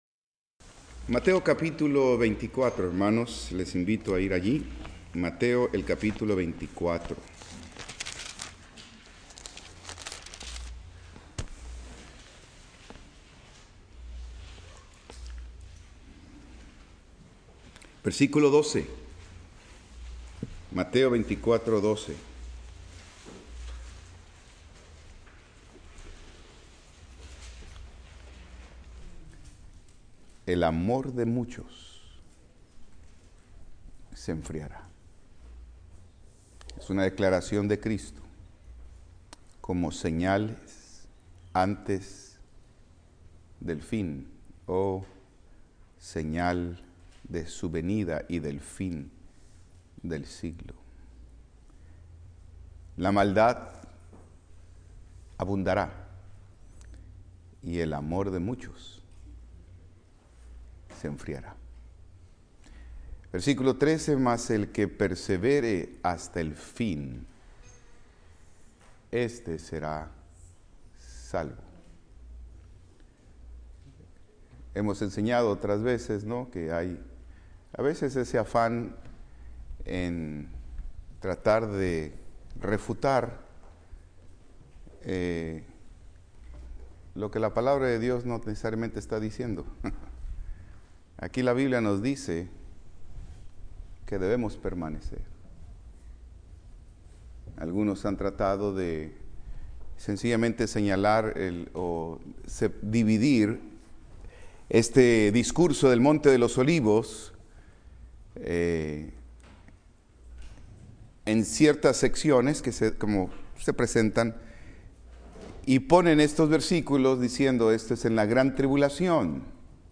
Servicio vespertino